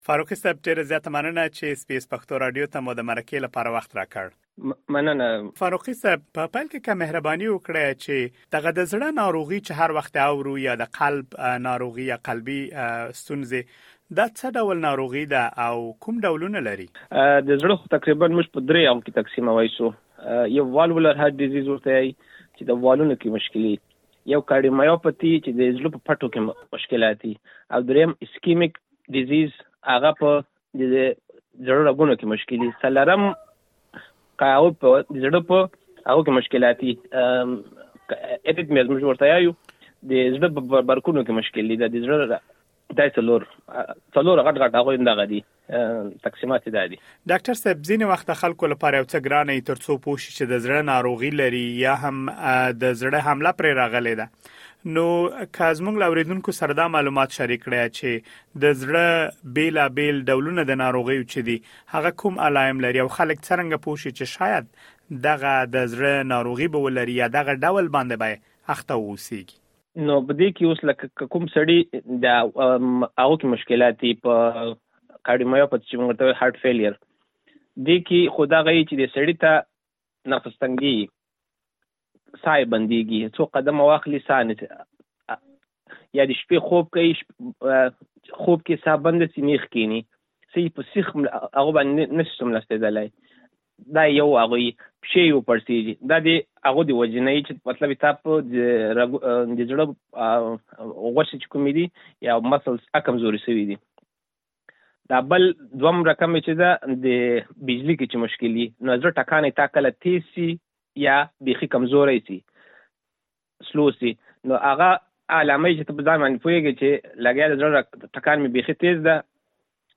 لا ډېر معلومات په مرکې کې اورېدلی شئ.